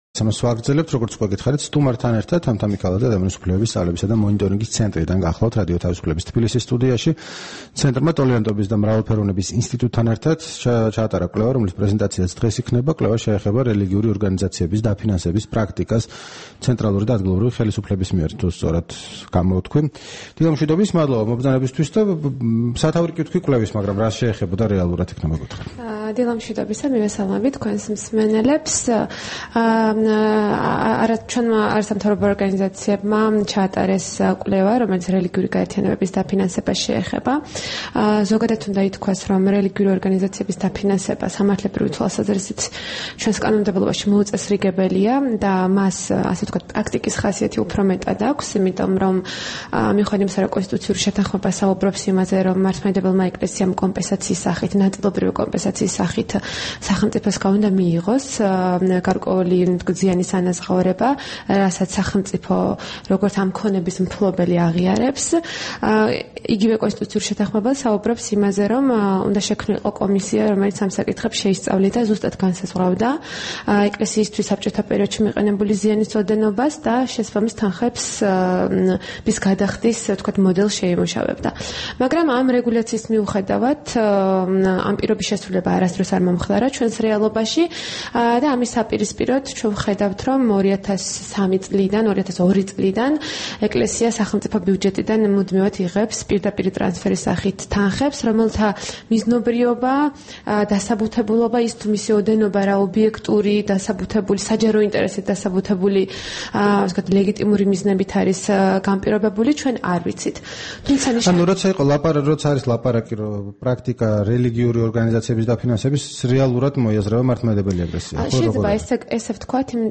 რადიო თავისუფლების თბილისის სტუდიაში სტუმრად იყო